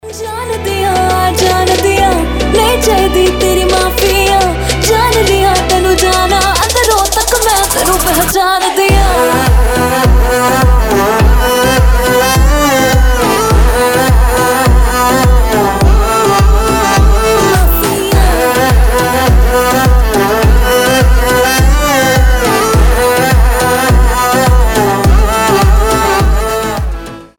• Качество: 320, Stereo
поп
женский вокал
восточные
индийские